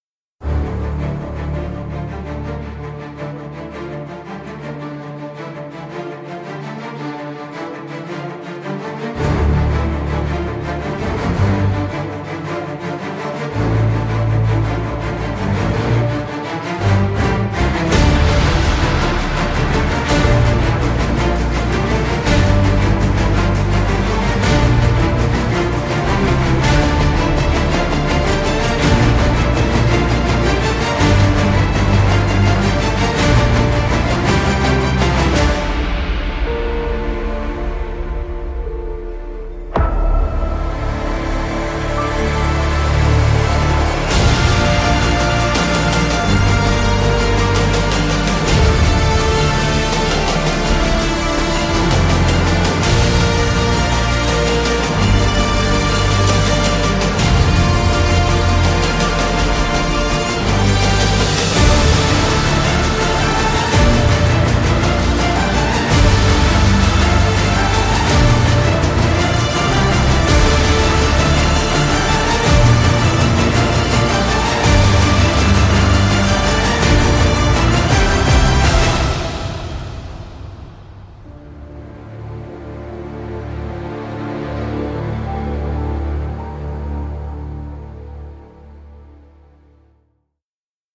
3. 管弦乐
•在大型音乐厅录制。
•四个不同部分：18个小提琴，16个中提琴，14个大提琴，12个低音。
•记录在舞台中央，宽阔的座位上。
•三个麦克风位置：关闭，房间，混合。